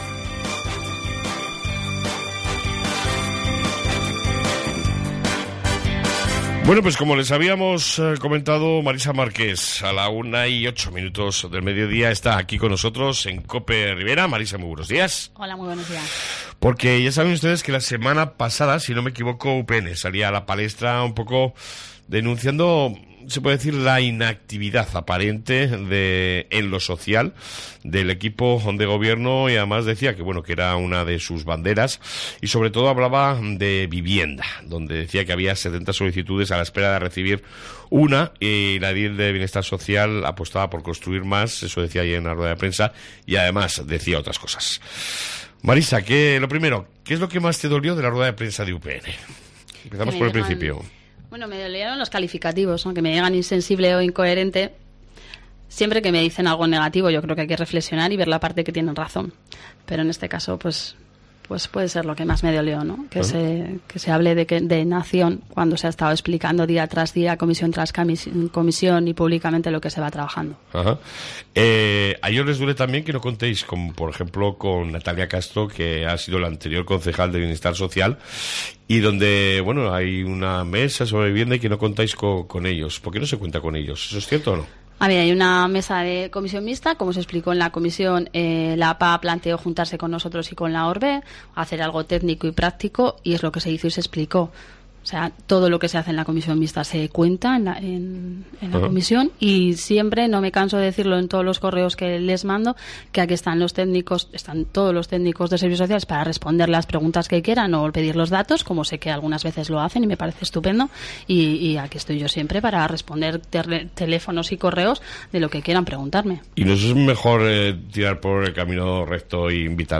ENTREVISTA SOBRE VIVIENDA Y SERVICIOS SOCIALES CON LA CONCEJAL MARISA MARQUÉS